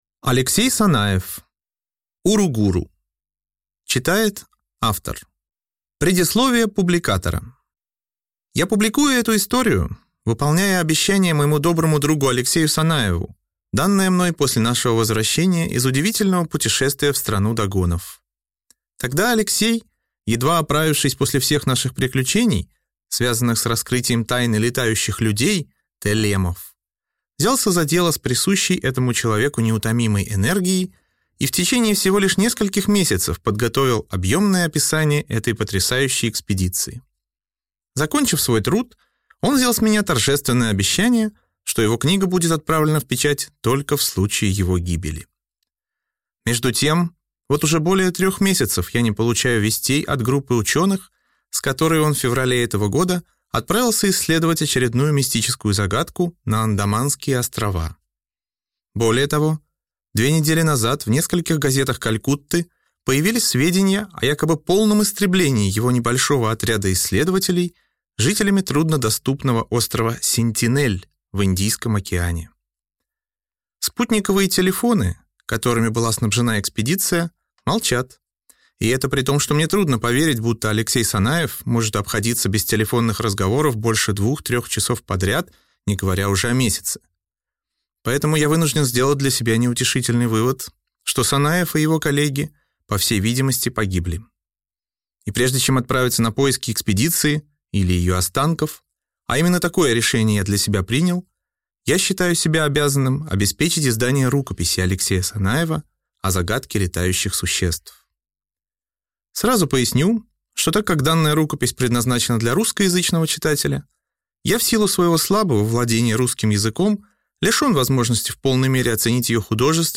Аудиокнига Уругуру | Библиотека аудиокниг